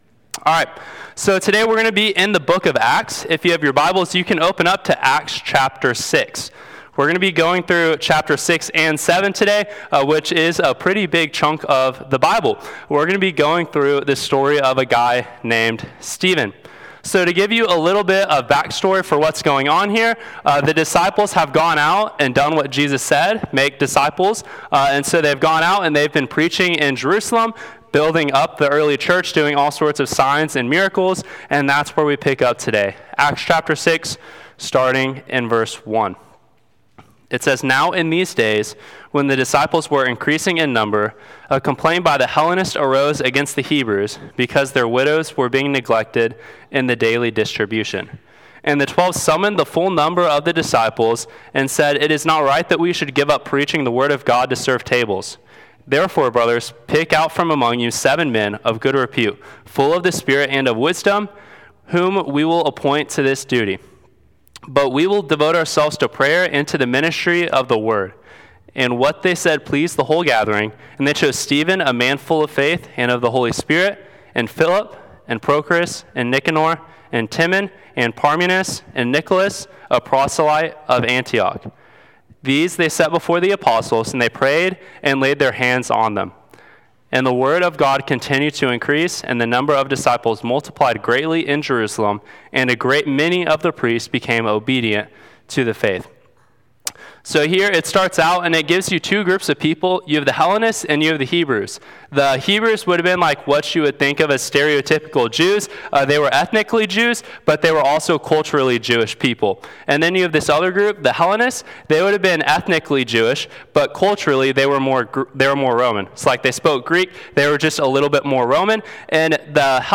Ordination Message: Stoning of Stephen | First Baptist Church Rockdale